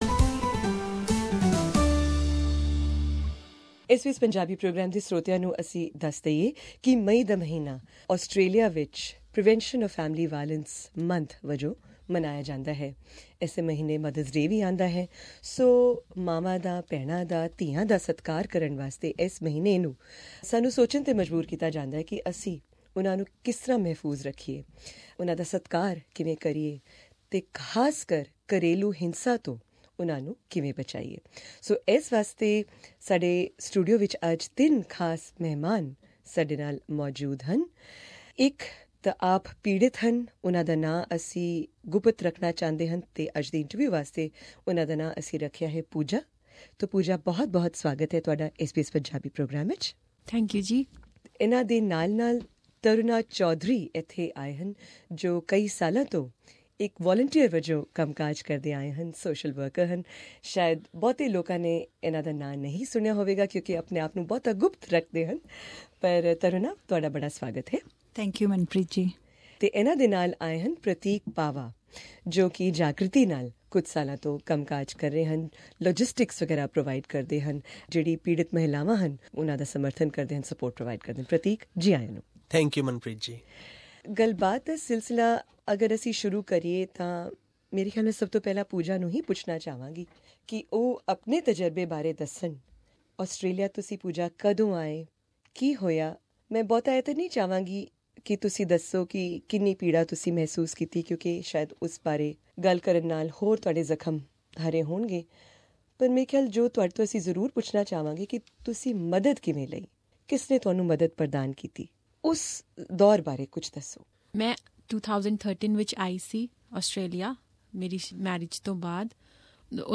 To explore this further, we held a panel discussion at SBS Punjabi's Melbourne studios, speaking to two volunteers who help victims of violence (especially those migrant women who don't have a permanent residency visa) and also a victim of violence herself, whose spousal visa sponsorship was withdrawn by her abusive husband.